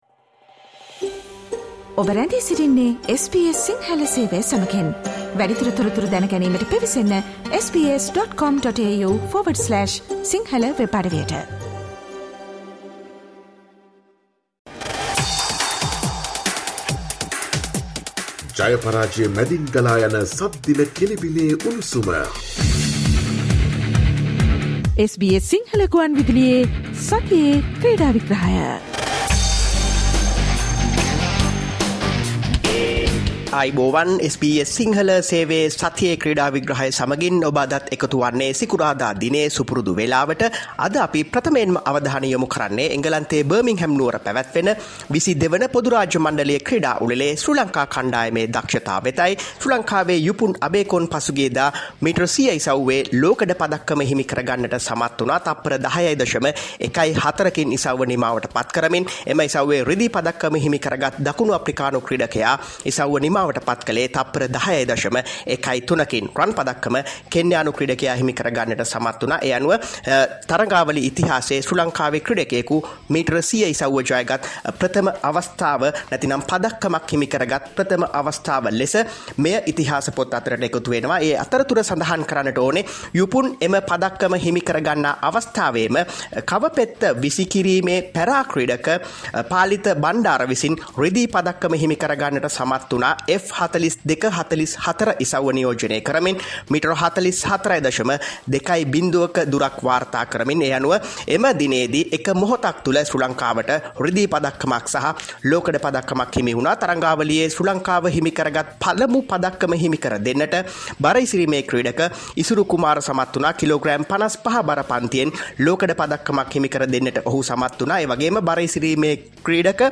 Stay tuned for SBS Sinhala Radio "Weekly Sports Wrap" on every Friday.